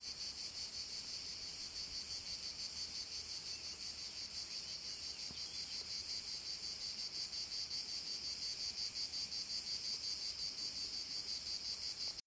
Cigale pygmée Tettigettula pygmea
Attention : on entend fortement la Cigale grise Cicada orni sur l'enregistrement : il faut tendre l'oreille pour bien différencier la Cigale pygmée qui émet des salves sonores plus longues et moins rapides...